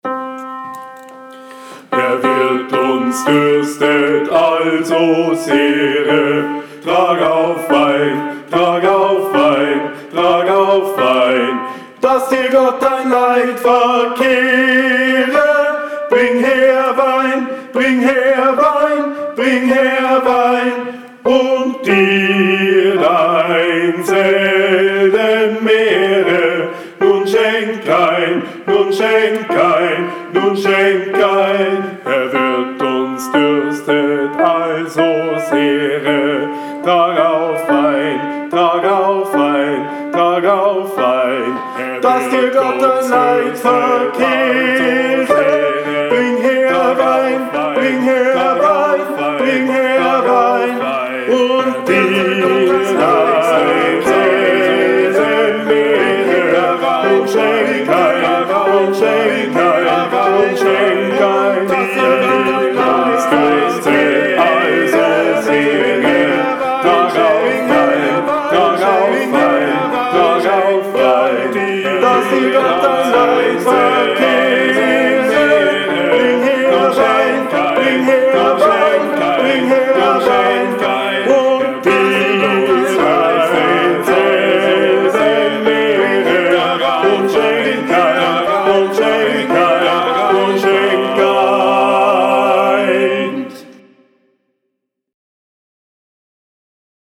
Alle Stimmen
26 Herr wirt uns durstet ALLE STIMMEN.mp3